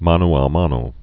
(mänō ä mänō)